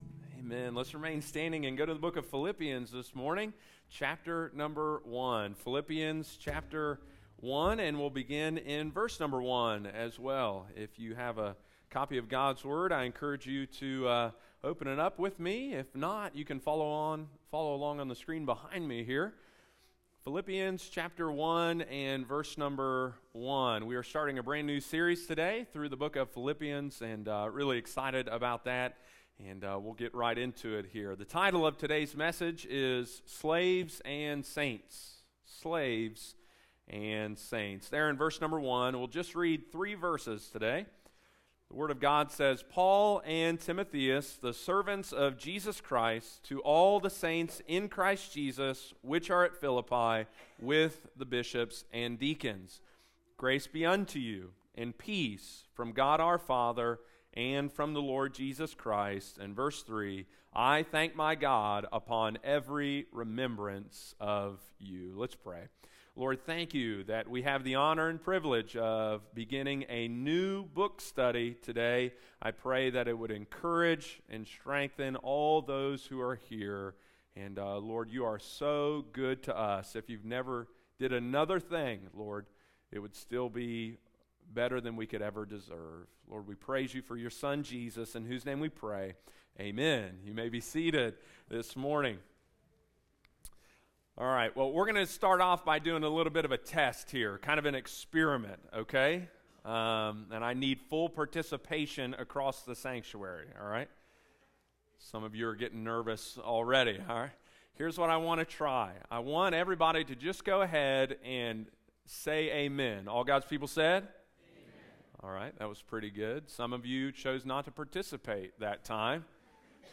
Slaves and Saints – Philippians Sermon Series – Lighthouse Baptist Church, Circleville Ohio